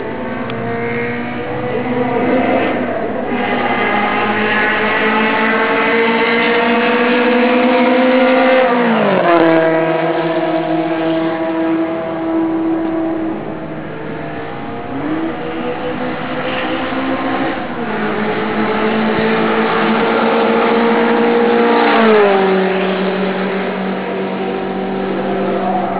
こちら(26秒204KB：２台目の方です)